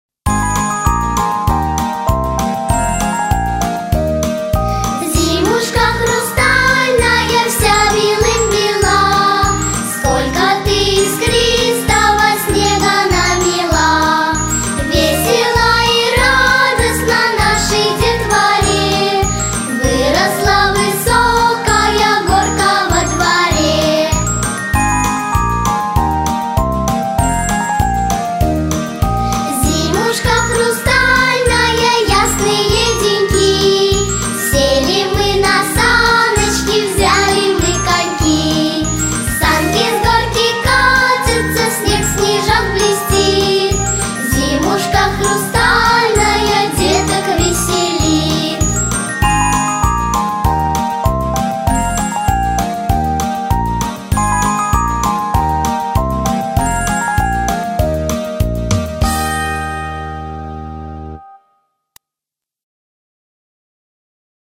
Песенки про зиму